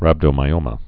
(răbdō-mī-ōmə)